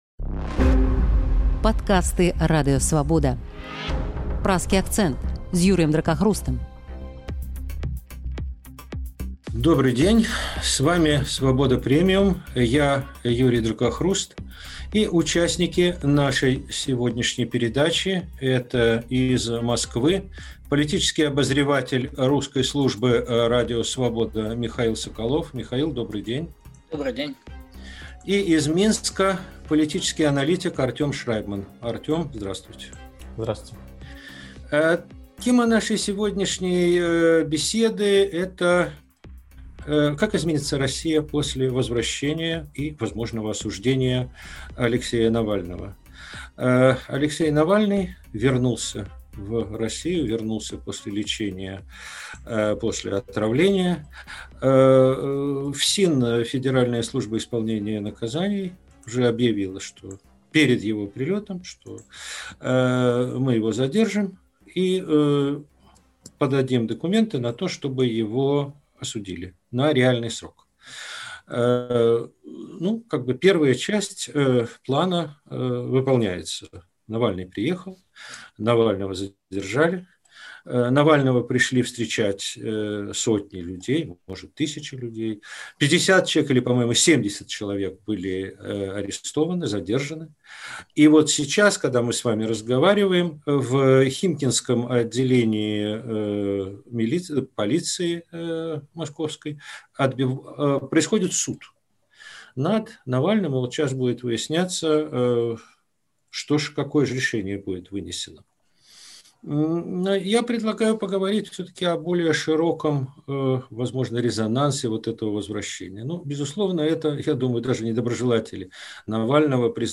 палітычны аналітык